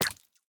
Minecraft Version Minecraft Version snapshot Latest Release | Latest Snapshot snapshot / assets / minecraft / sounds / mob / tadpole / hurt2.ogg Compare With Compare With Latest Release | Latest Snapshot
hurt2.ogg